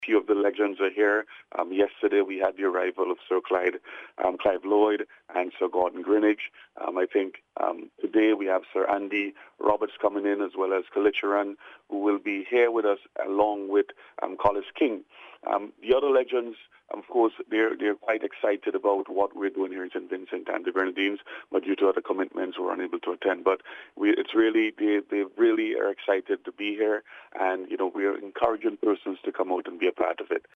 Minister of Tourism and Culture Carlos James in an interview with NBC News encouraged all Vincentians to arrive at the Arnos Vale Playing field early to enjoy to cricket festival.